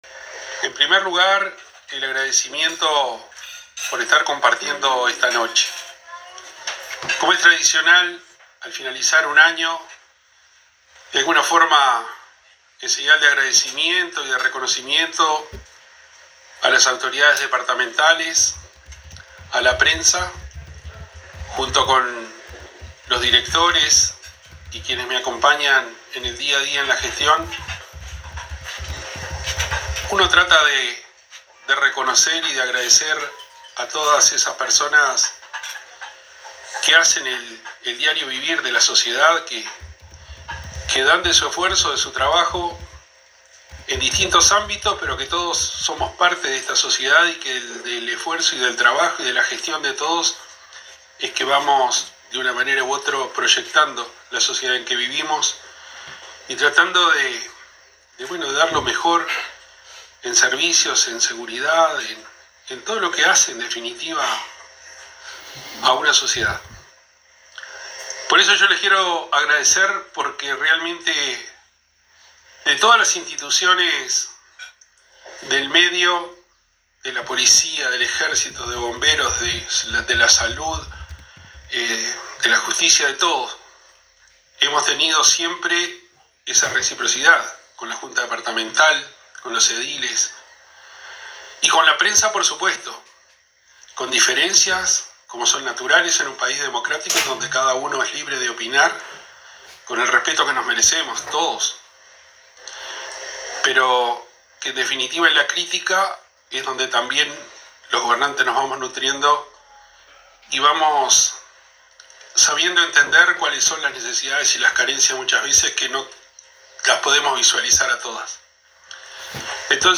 El Intendente Wilson Ezquerra tuvo palabras de elogio y agradecimiento hacia instituciones y medios de comunicación del departamento, en una cena de despedida del año 2024 organizada este martes en el Club de Campo de Tacuarembó, en la cual expresó sus mejores deseos para la temporada navideña que se aproxima.
Escuche al Intendente Ezquerra aquí: